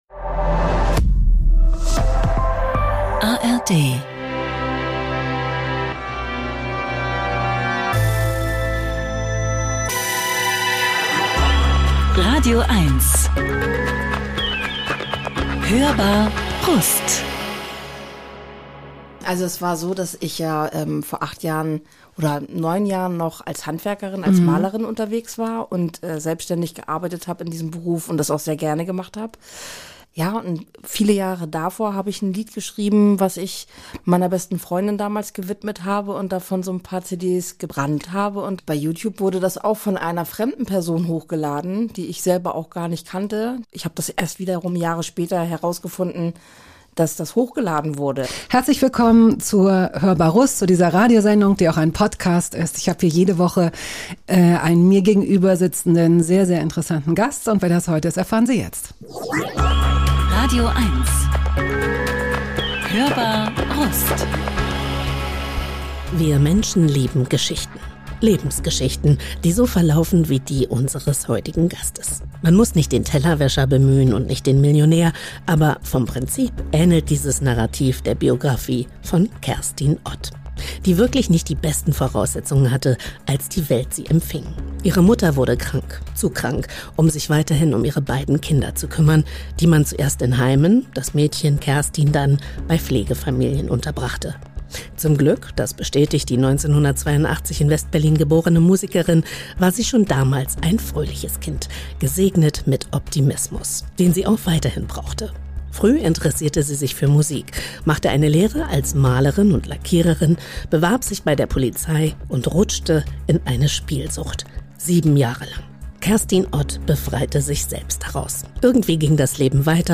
Unser heutiger Gast ist eine ganze Agentur oder Redaktion in Personalunion.